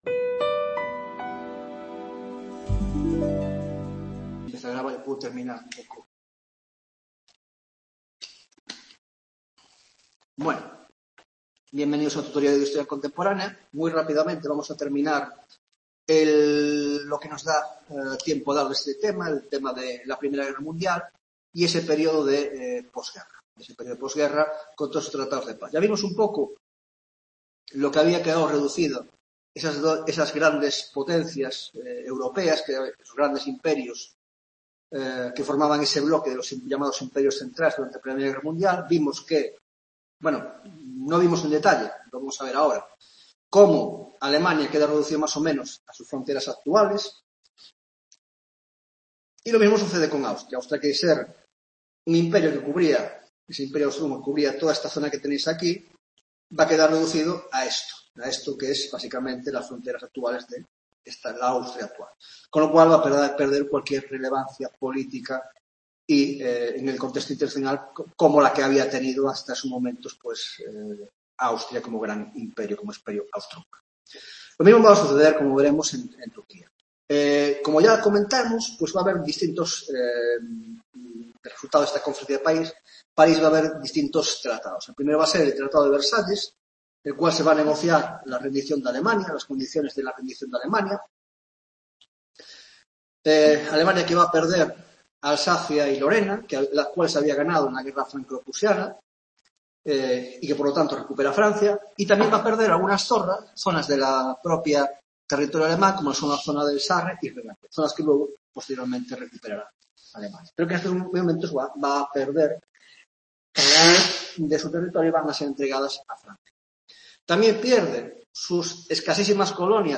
20ª tutoria de Historia Contemporánea - La I Guerra Mundial: Introducción (2ª parte) - 1) La Paz y los Tratados de la Paz, 2) La Sociedad de Naciones